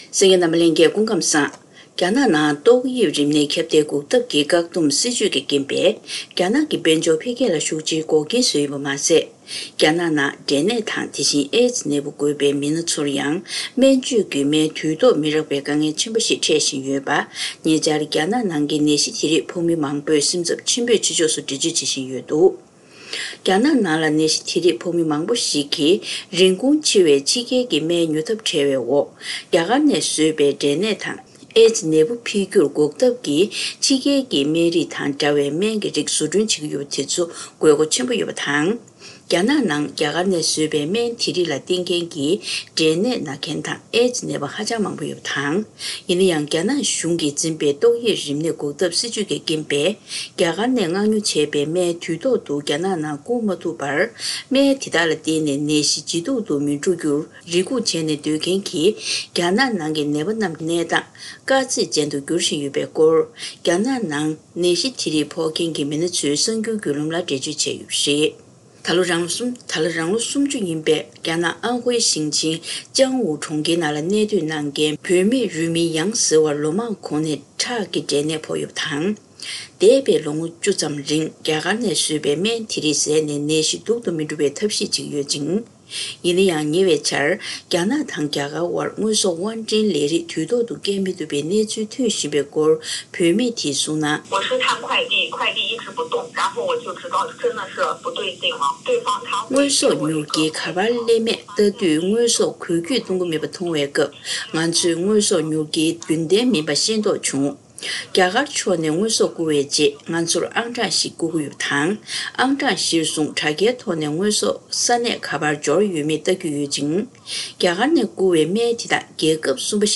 སྒྲ་ལྡན་གསར་འགྱུར། སྒྲ་ཕབ་ལེན།